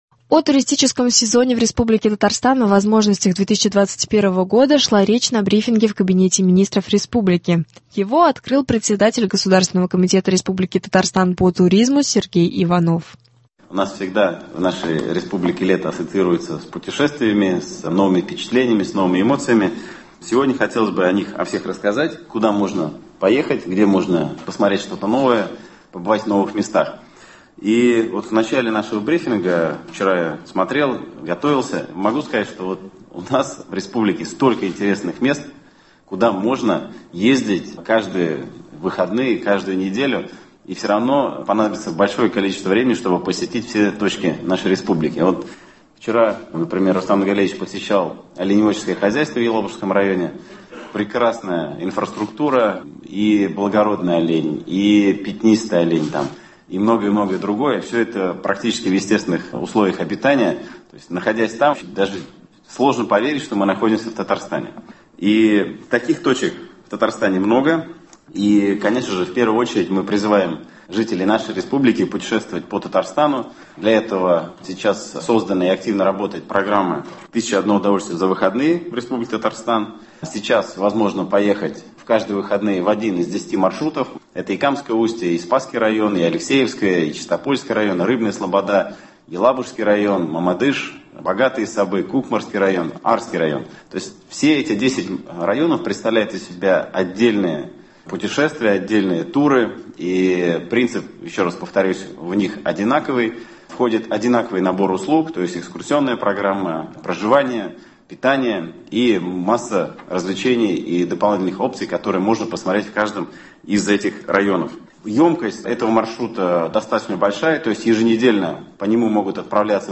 О туристическом сезоне в Республике Татарстан, о возможностях 2021 года шла речь на брифинге в Кабинете министров республики.